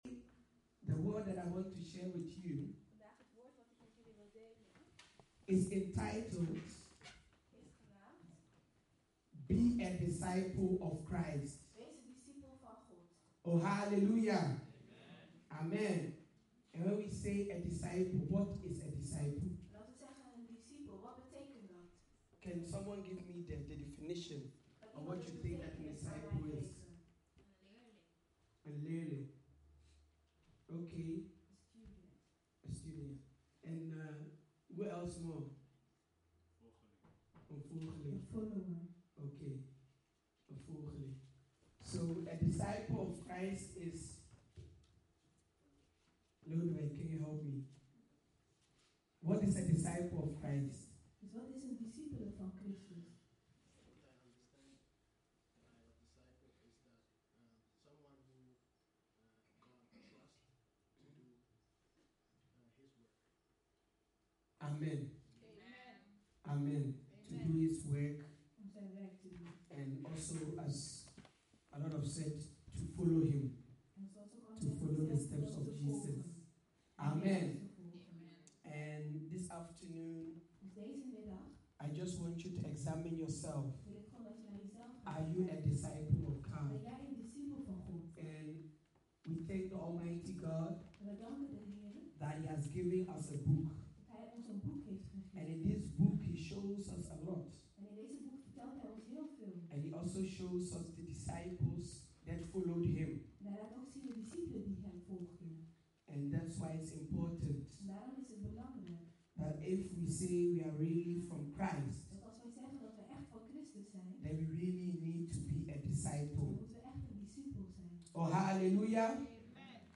Dutch Assembly